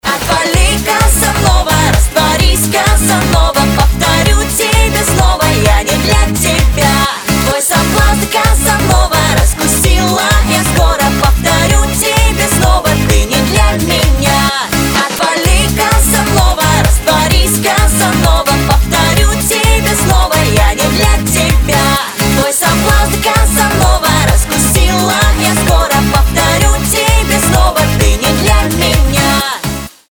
• Качество: 320, Stereo
поп
dance